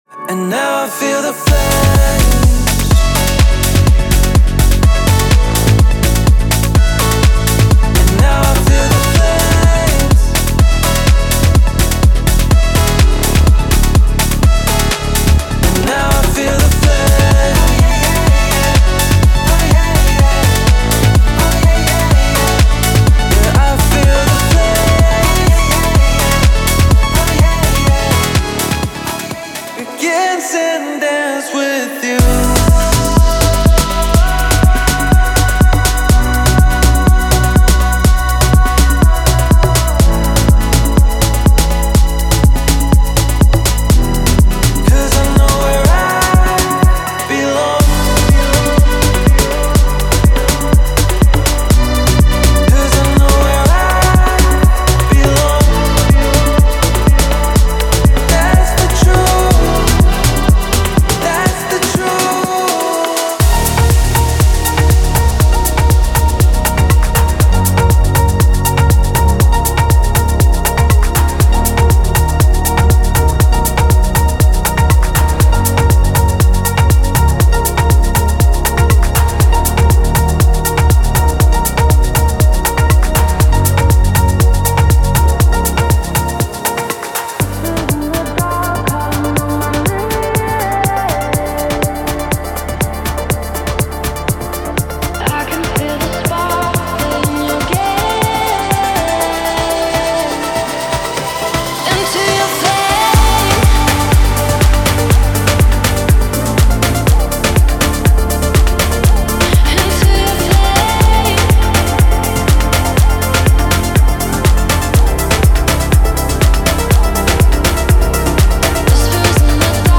沉浸于现代非洲浩室音乐的律动之中！
它包含强劲的节奏、深情的音色以及完整的 Ableton 工程文件，完美捕捉了现代非洲电子音乐温暖而迷人的能量。
• 530 多个采样 – 单次采样、鼓循环、踩镲、底鼓、打击乐等等
• 70 多个 Serum 预设 – 主音、贝斯、合成器音垫、拨弦（兼容 Serum 2）